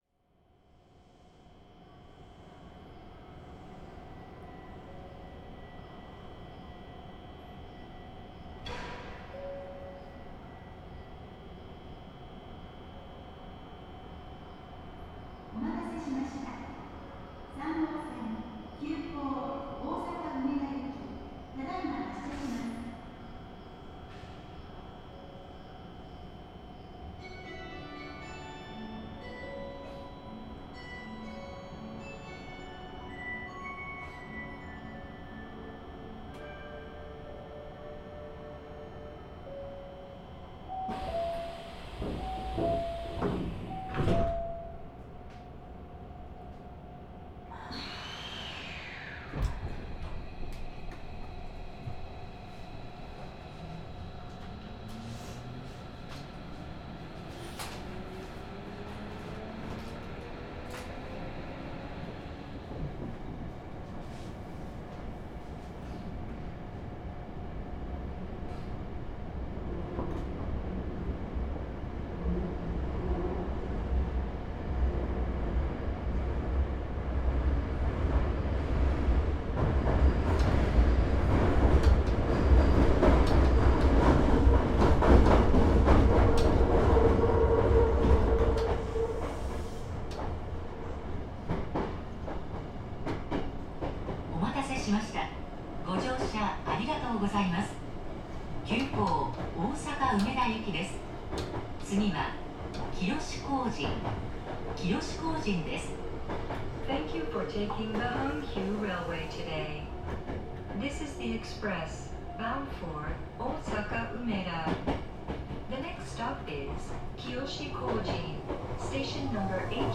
・走行音(全区間)(ソフト変更後)(49.7MB★)←new!!!
収録区間：宝塚本線(急行) 宝塚→大阪梅田 制御方式：VVVFインバータ制御(東芝2レベルIGBT) 主電動機：SEA-538A(190kW) 2013(平成25)年に登場した神宝線用車両。
これらは走行音の低減にも役立っており、9000系に比べ4dB低減を達成してしまった。